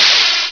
menuchoose.wav